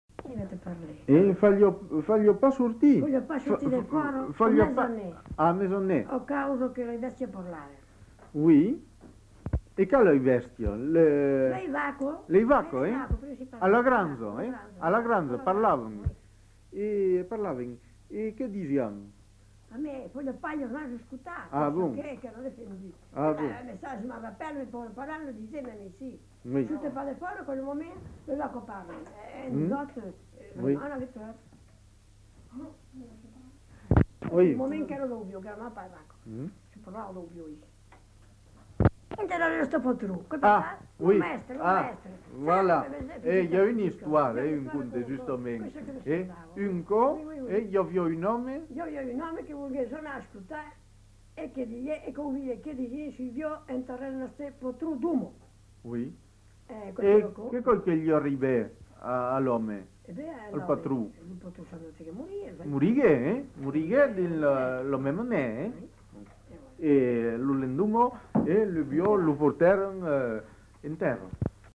Aire culturelle : Périgord
Lieu : Cendrieux
Genre : conte-légende-récit
Effectif : 1
Type de voix : voix de femme
Production du son : parlé